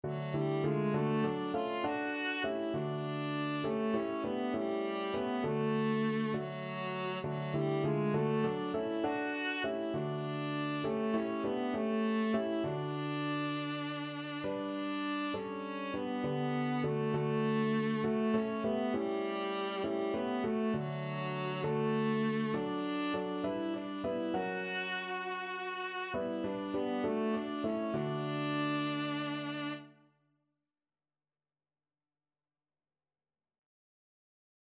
Viola
D major (Sounding Pitch) (View more D major Music for Viola )
6/8 (View more 6/8 Music)
Classical (View more Classical Viola Music)